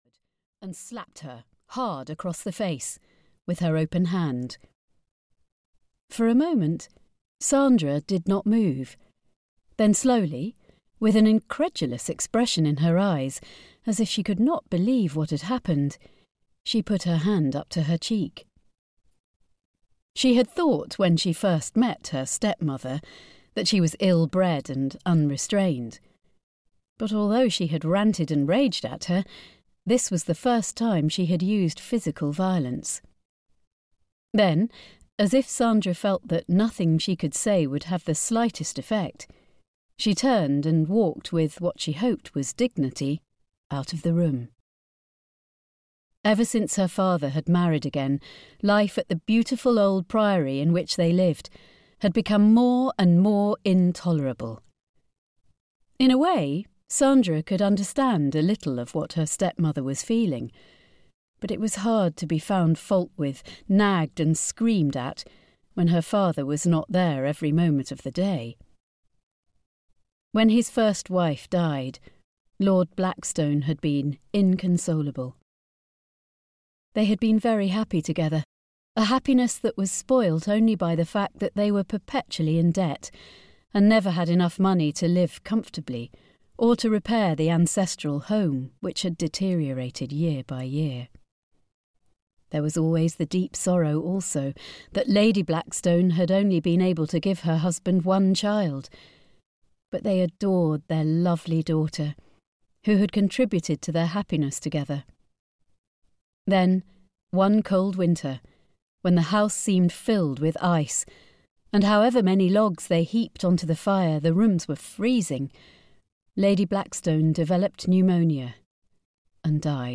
Secrets (EN) audiokniha
Ukázka z knihy
• InterpretSophie Aldred